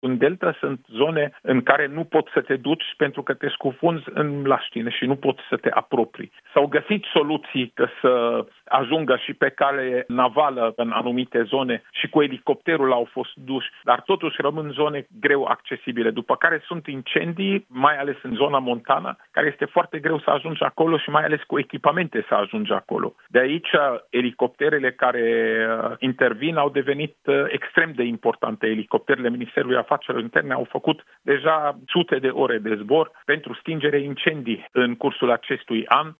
Șeful DSU, Raed Arafat: Elicopterele MAI au efectuat deja sute de ore de zbor pentru stingerea incendiilor în cursul acestui an